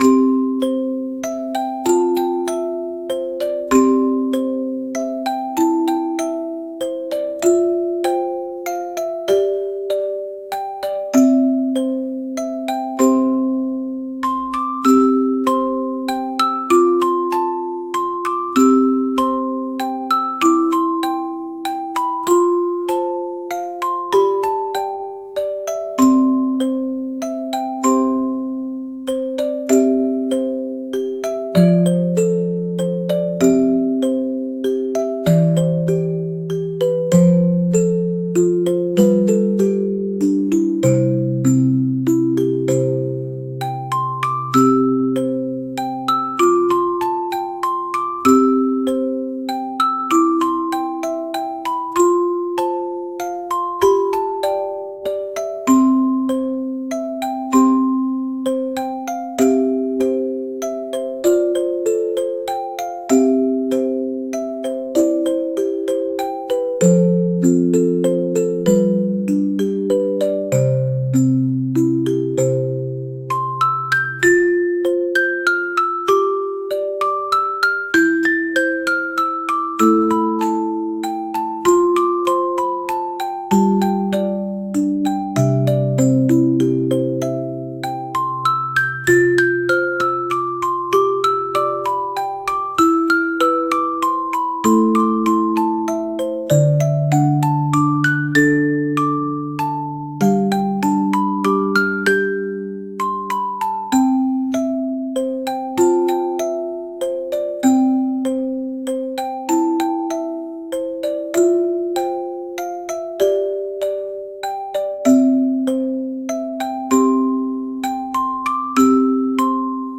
「ほのぼの」
「オルゴール」